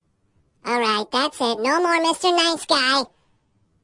描述：一个人声线的记录和处理，就像一个卡通花栗鼠说的一样。 录音是在Zoom H4n上进行的。使用MOTU Digital Performer中的Spectral Effects进行操纵